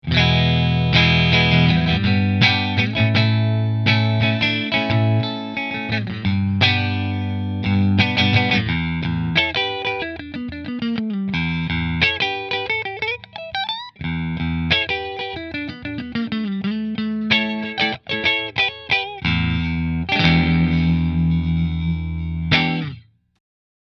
• Three Custom Wound P90s
New Orleans Guitars Voodoo Quilt Middle Through Fender